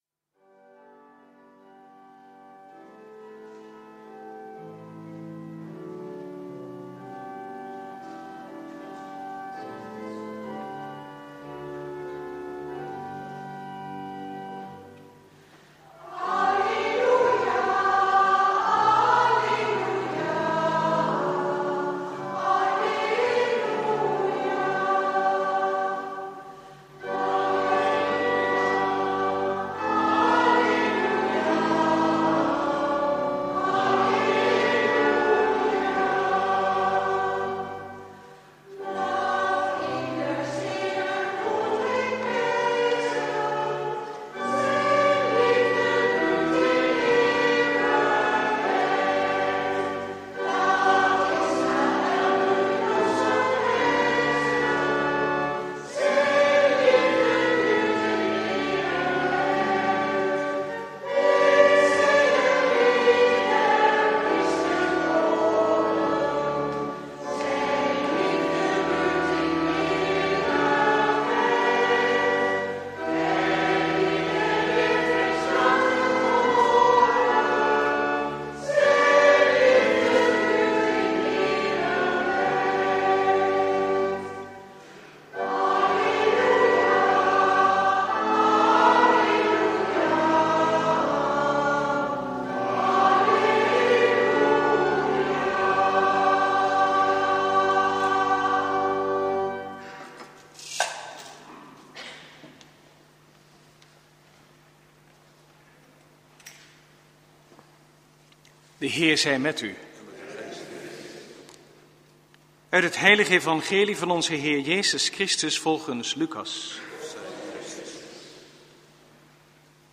Preek tijdens de Paaswake en Paaszondag, jaar C, 26/27 maart 2016 | Hagenpreken
Eucharistieviering beluisteren (MP3)